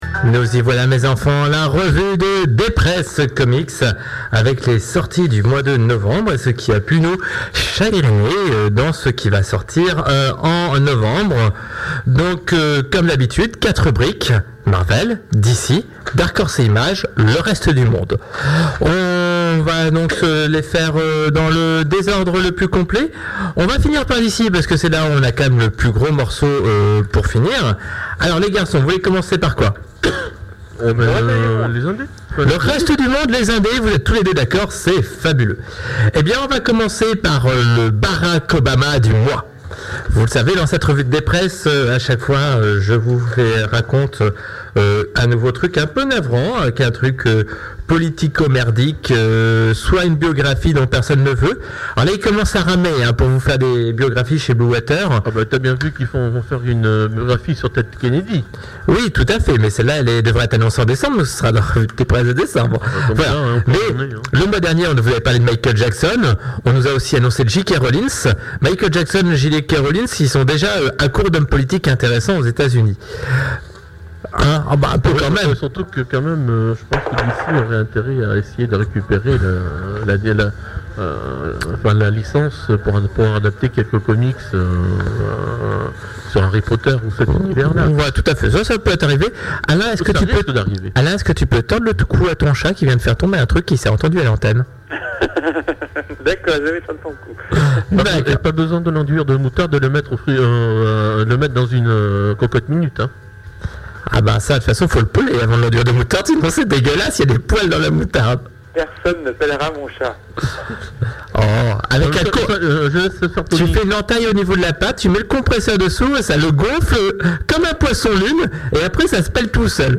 Aleph : l’émission radio » La revue de dépress’ Comics de novembre 2009